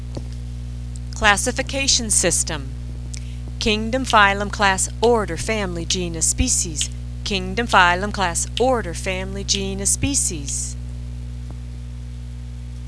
But sometimes adding words and making commentary is unnecessary, especially when you most often want this list in a clean rapid manner. Plus these words made such a natural and easy rhythm that I didn't see the need to embellish it.